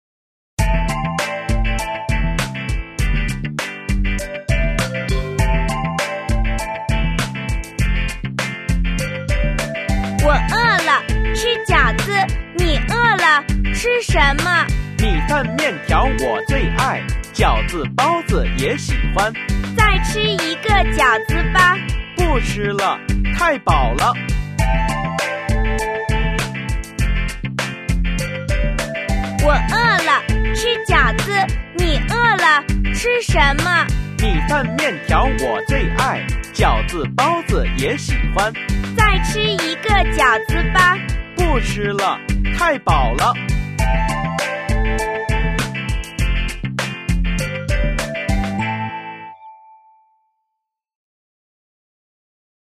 Cùng hát nhé